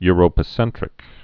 (y-rōpə-sĕntrĭk)